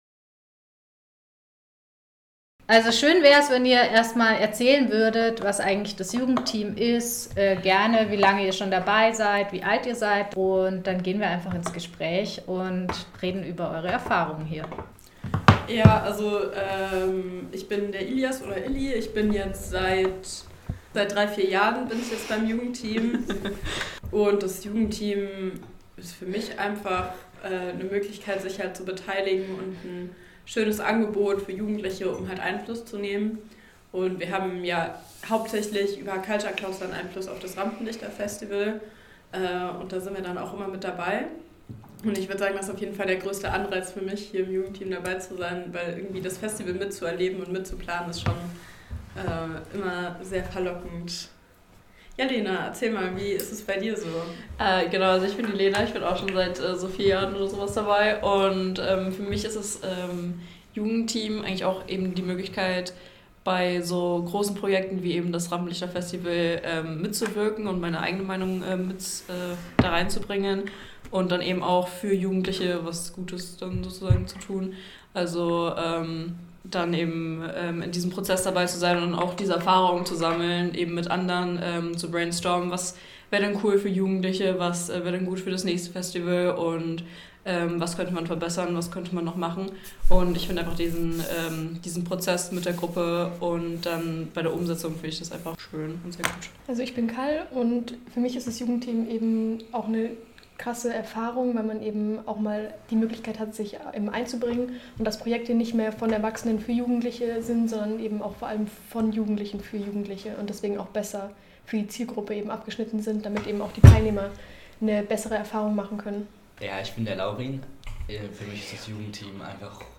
Außerdem freuen wir uns über einen ganz besonderen Beitrag von unserem Jugendteam: Im Rahmen ihrer Mitwirkung am Tanz- und Theaterfestival Rampenlichter haben sie einen Podcast aufgenommen, den ihr euch direkt hier anhören könnt.
Jugendteam-interview-fertig-gecuttet_2.mp3